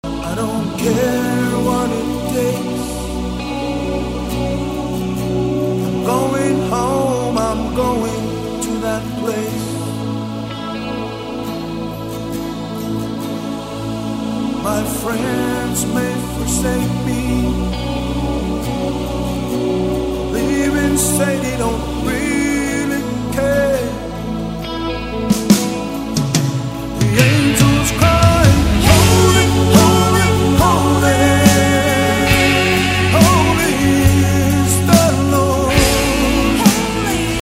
2007's Top Native Gospel Album